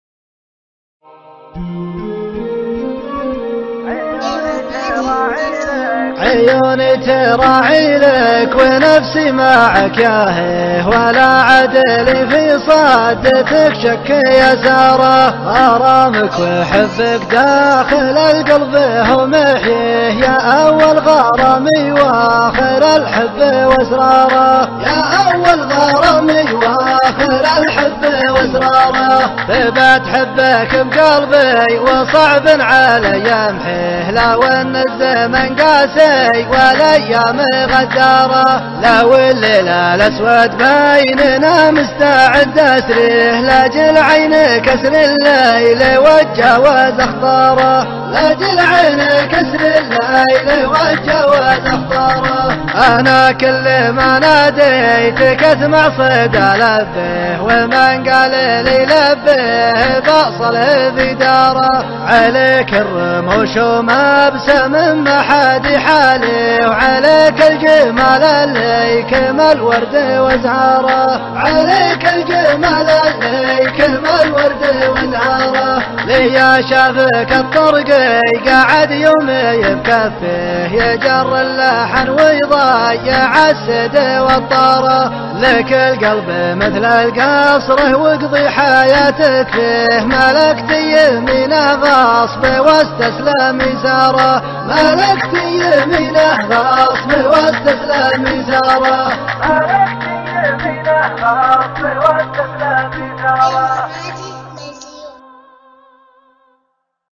مسرع